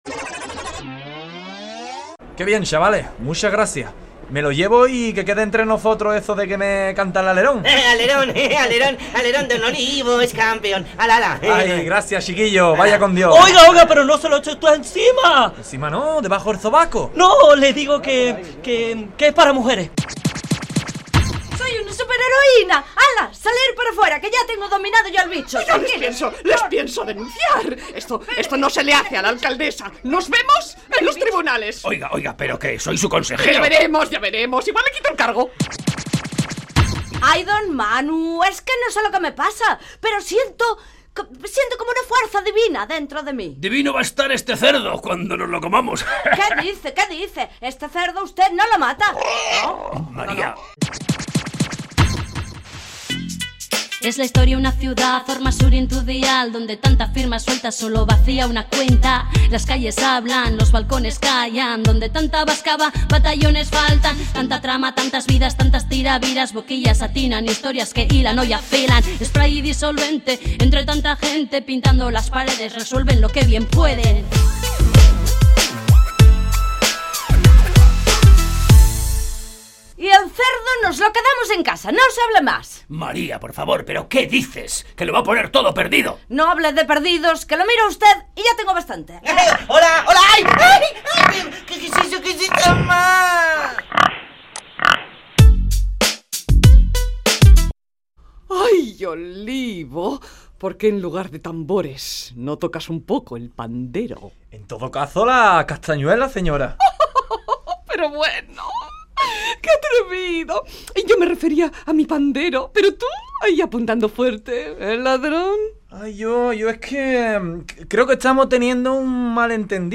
Entrega número 29 de la Radio-Ficción “Spray & Disolvente”